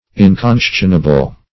Inconscionable \In*con"scion*a*ble\, a.